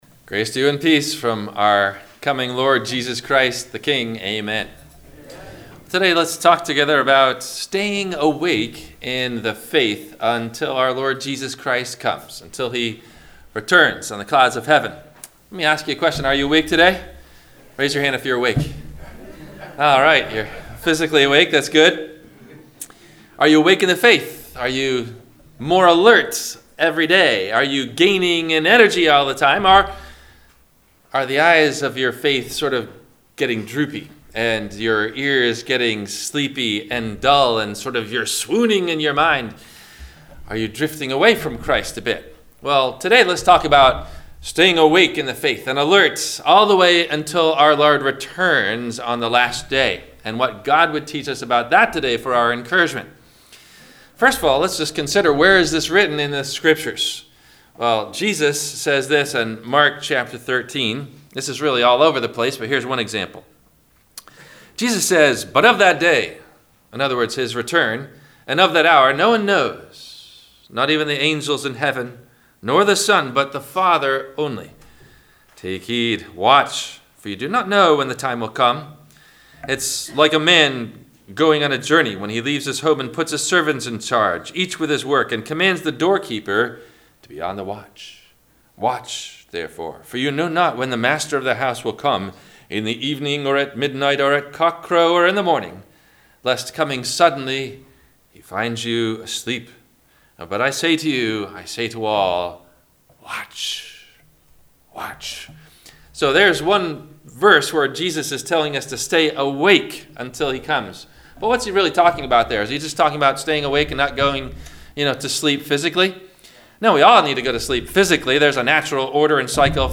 - Sermon - December 08 2019 - Christ Lutheran Cape Canaveral